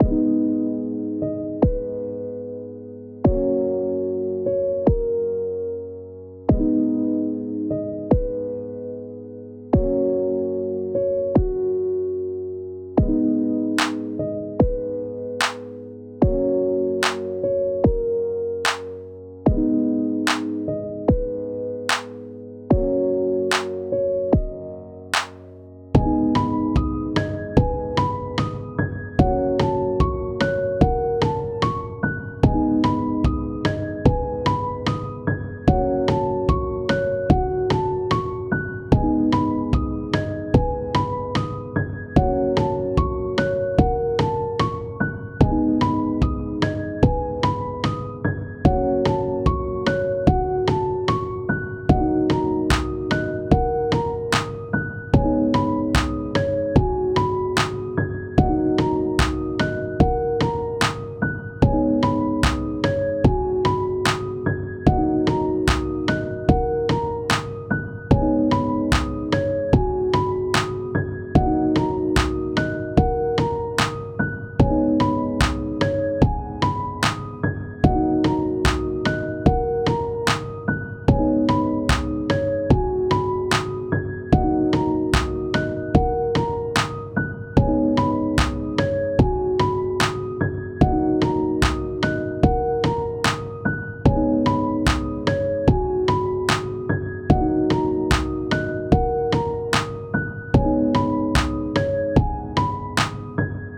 カフェで流れてそうなチルでおしゃれな一曲です！
BPM：74 キー：B ジャンル：ゆったり、おしゃれ 楽器：ピアノ、アンビエント